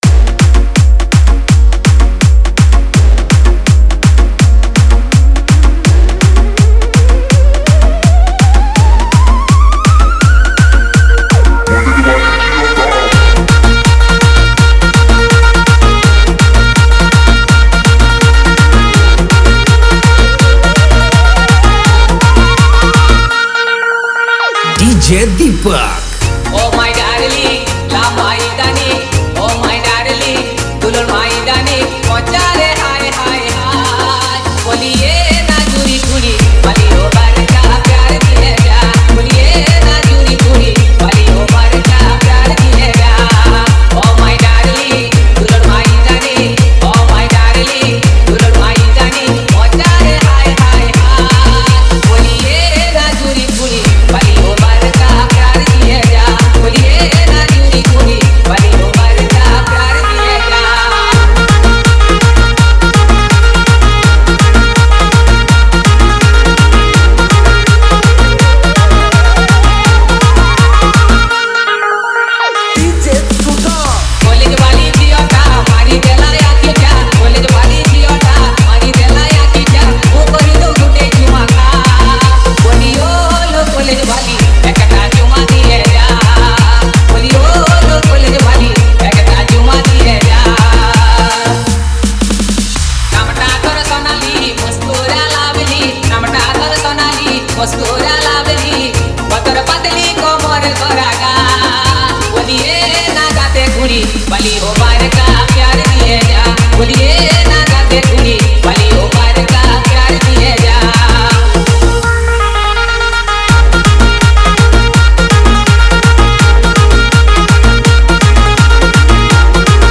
Category : Others Remix Songs